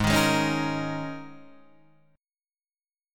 Absus2sus4 chord